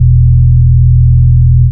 Soft Bass 65-05.wav